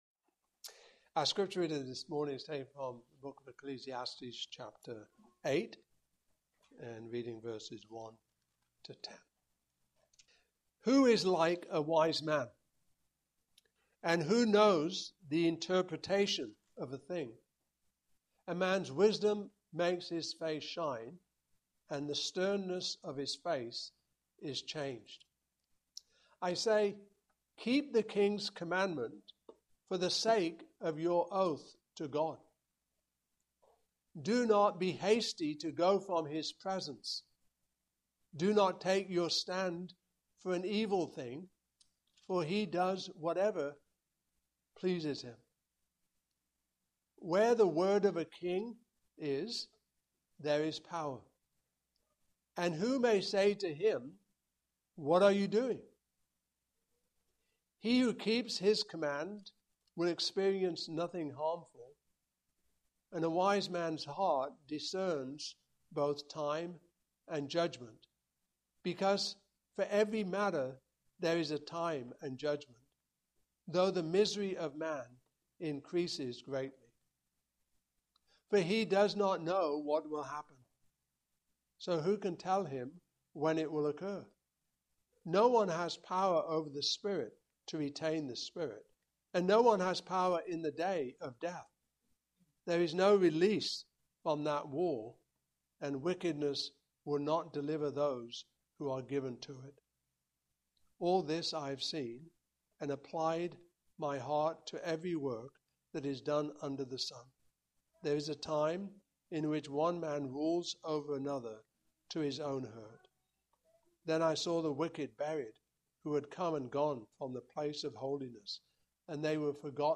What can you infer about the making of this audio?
Passage: Ecclesiastes 8:1-10 Service Type: Morning Service